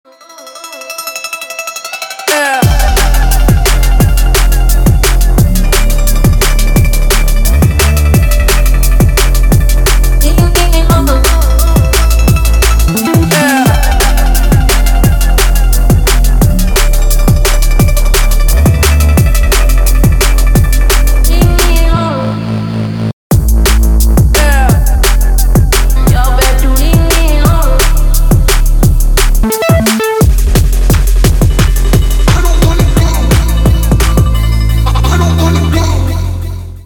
DubStep / DnB рингтоны